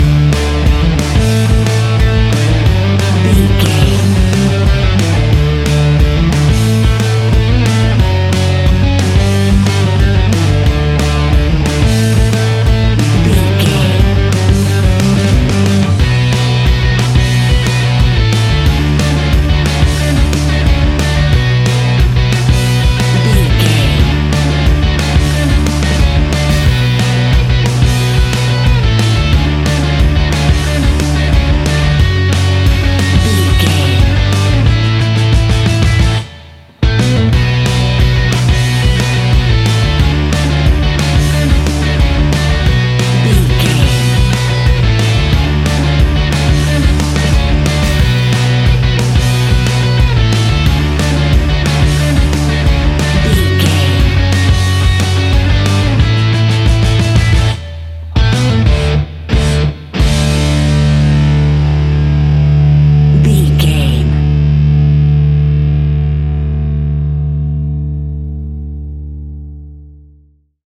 Fast paced
Ionian/Major
D
hard rock
distortion
punk metal
rock instrumentals
rock guitars
Rock Bass
Rock Drums
heavy drums
distorted guitars
hammond organ